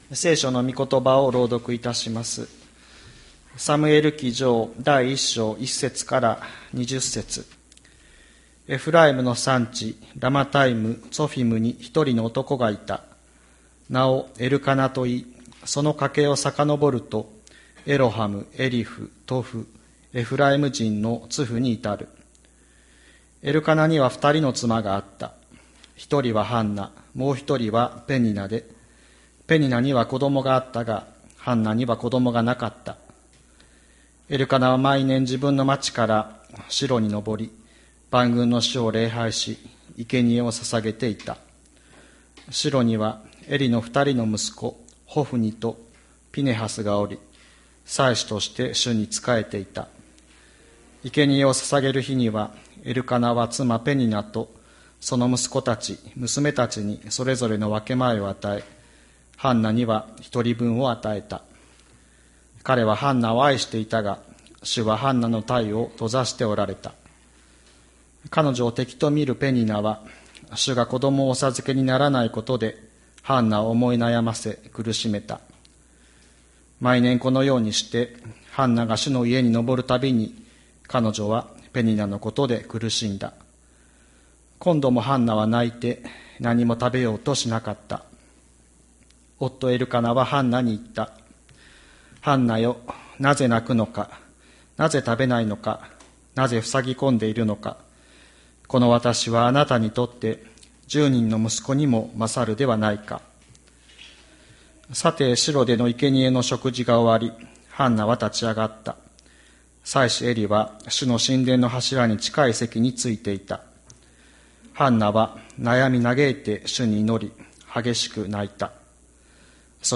2020年11月22日朝の礼拝「こころ注ぎ出す祈り」吹田市千里山のキリスト教会
千里山教会 2020年11月22日の礼拝メッセージ。